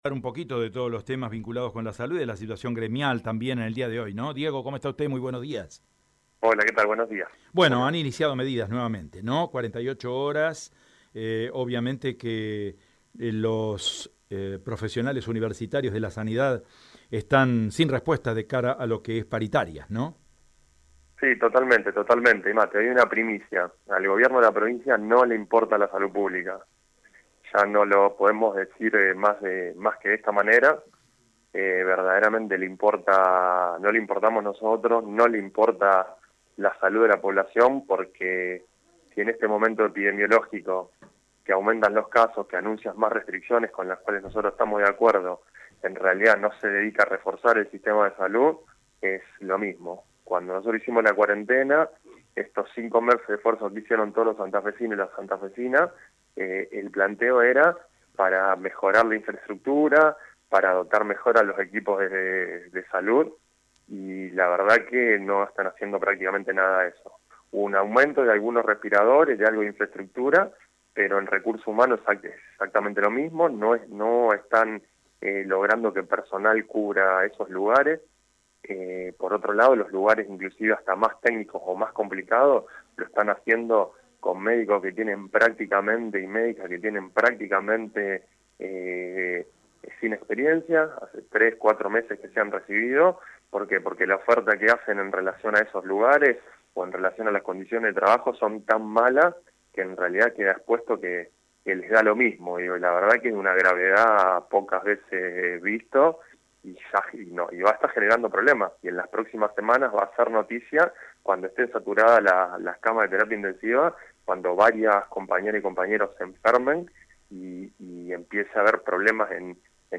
«En semanas será noticia cuando estén todas las camas saturadas y todos los compañeros estén enfermos sin poder cubrir la demanda, lo del gobierno es un marketing y creemos que mienten en lo que dicen. Cuentan los muertos e infectados pero no se habla del sistema de salud», dijo visiblemente enojado con las autoridades.